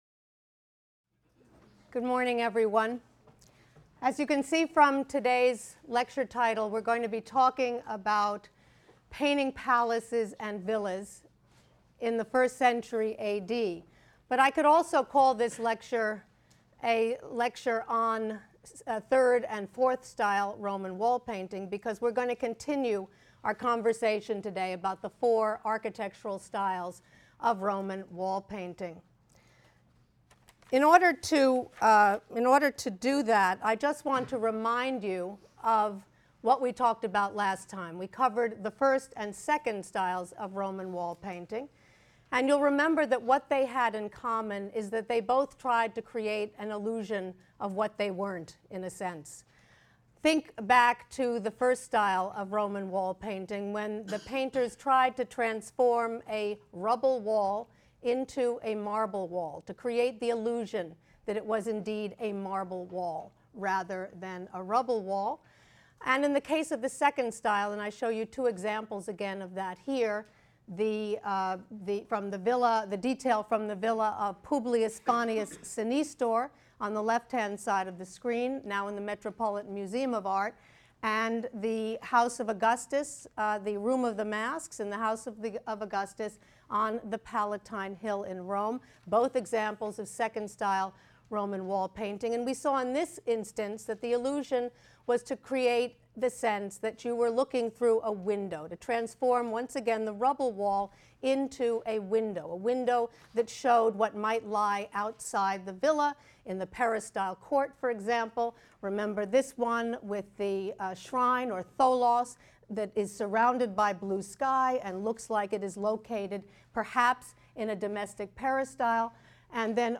HSAR 252 - Lecture 7 - Gilding the Lily: Painting Palaces and Villas in the First Century A.D. | Open Yale Courses